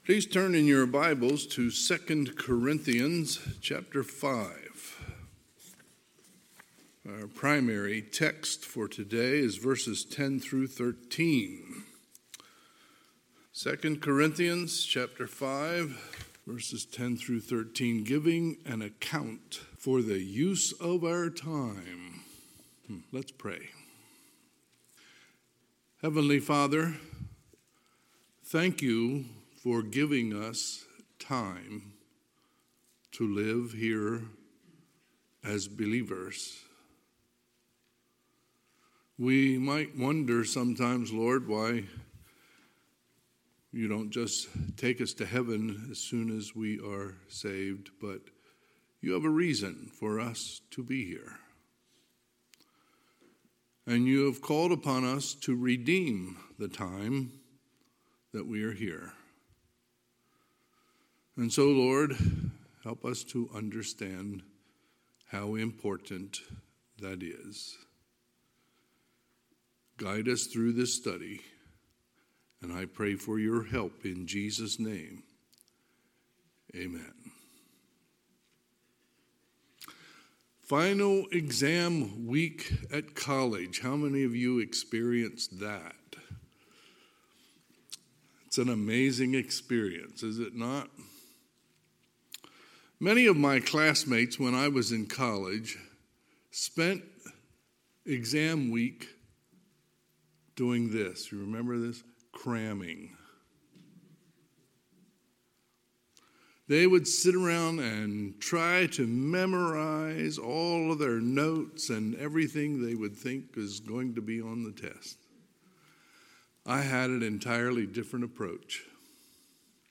Sunday, November 13, 2022 – Sunday AM
Sermons